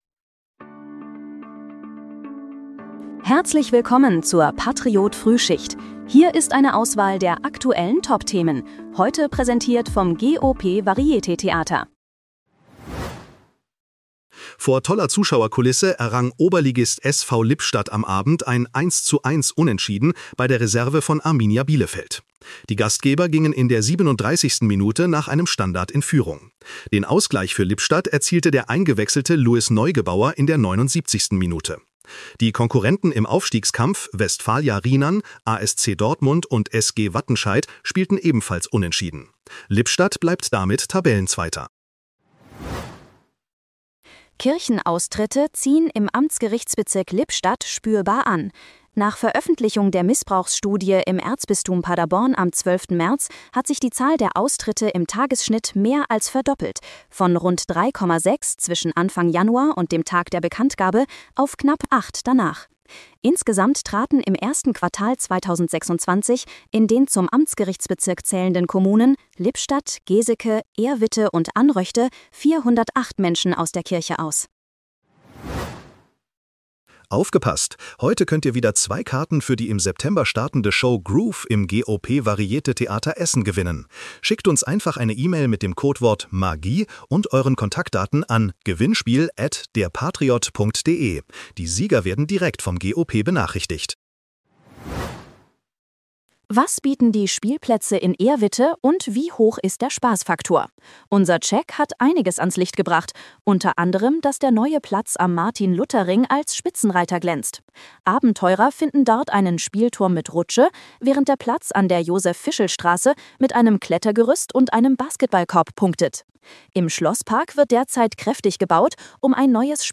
Willkommen zur Patriot-Frühschicht. Dein morgendliches News-Update
mit Hilfe von Künstlicher Intelligenz.